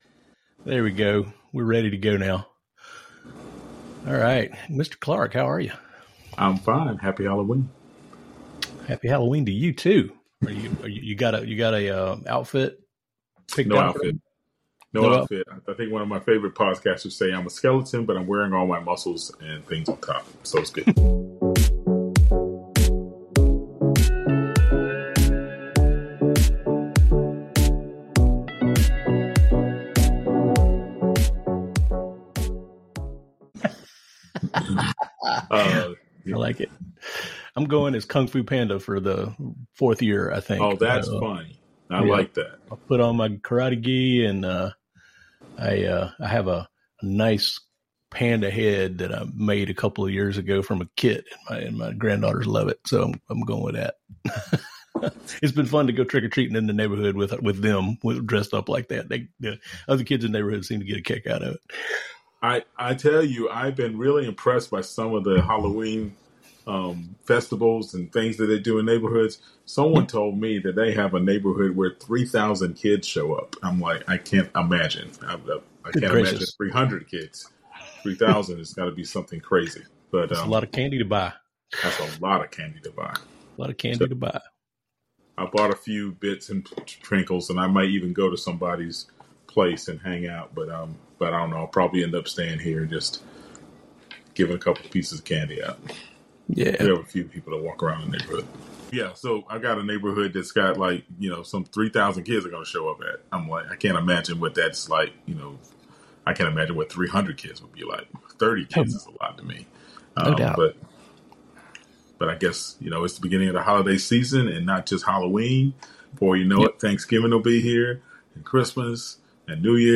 "Care Tech & Tips" is like tech support meets family therapy, with two guys who’ve been there. The podcast that helps you bring order to caregiving chaos. Get smarter with tech, money, and care strategies—without the overwhelm.